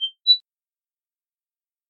Звук загрузки завершен